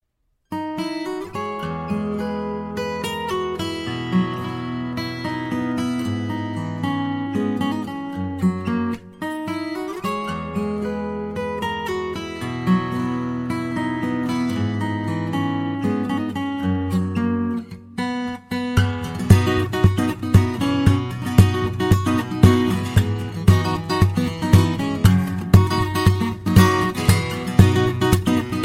RELATED GUITAR RINGTONES